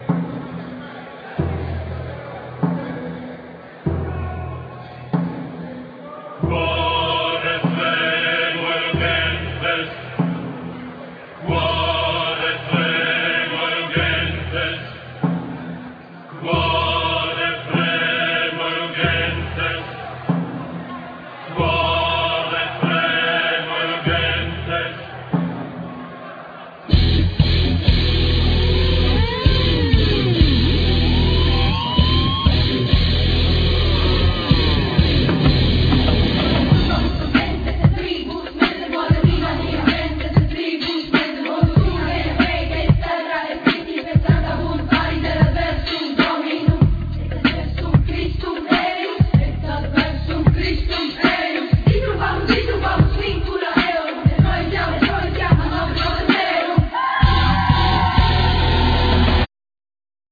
Keyboards,Sampler,Kokle,Fiddle,Vocal and naration
Guitars
Accoustic guitar
French horn